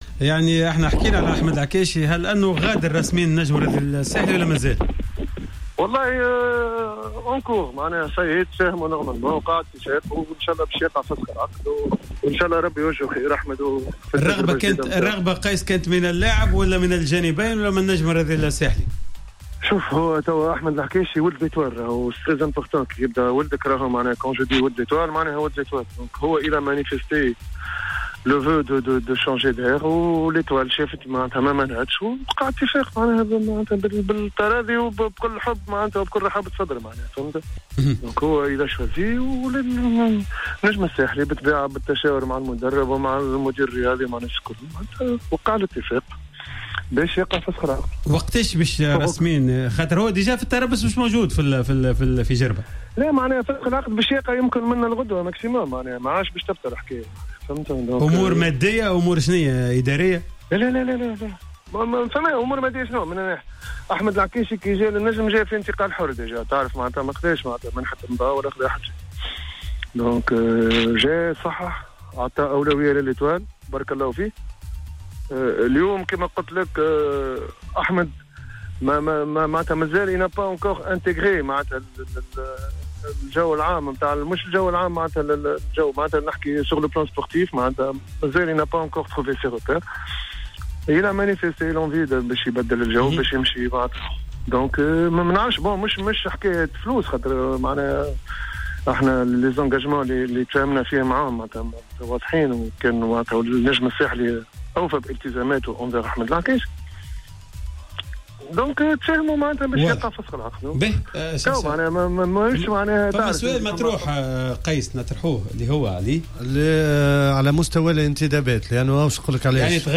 مداخلة في حصة "Planète Sport"